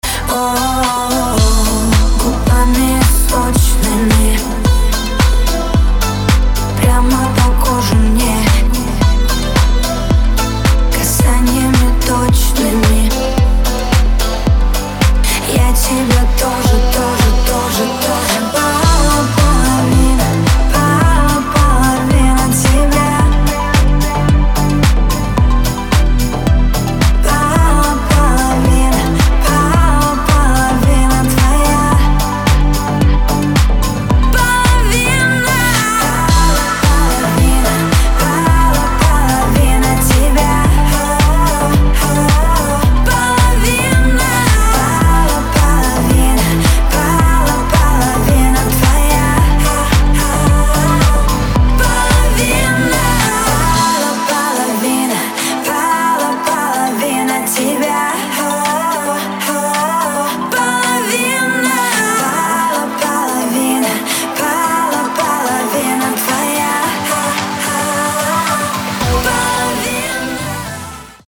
• Качество: 224, Stereo
поп
женский вокал
dance
спокойные
чувственные